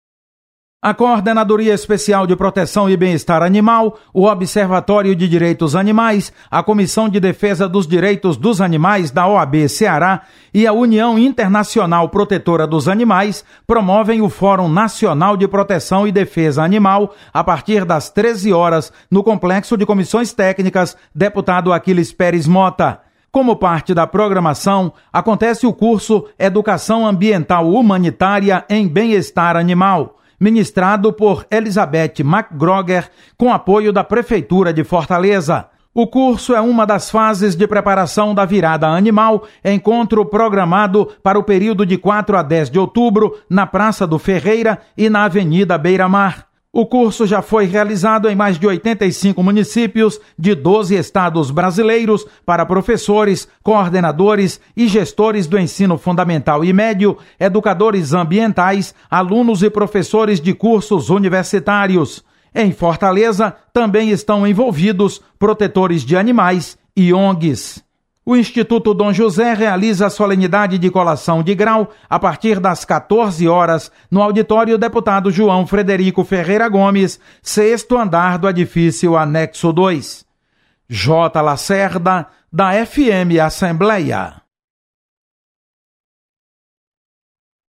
FM Assembleia